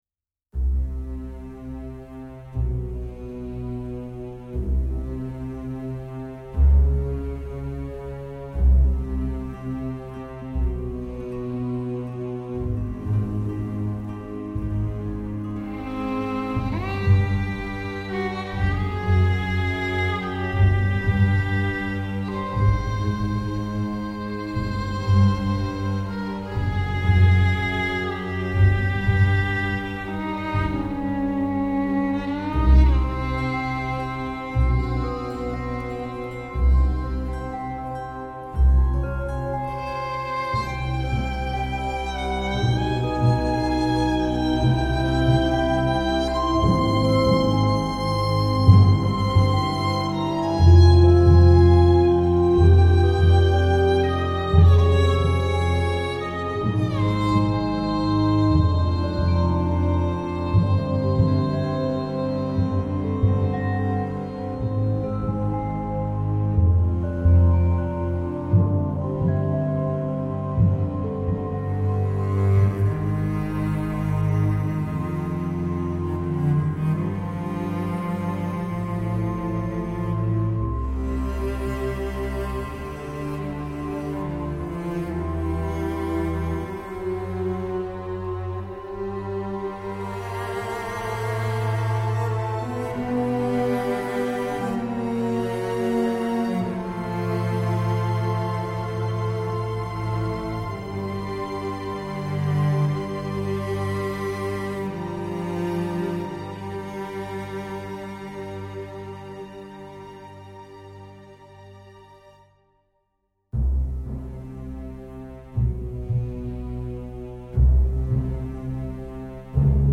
solo violin melody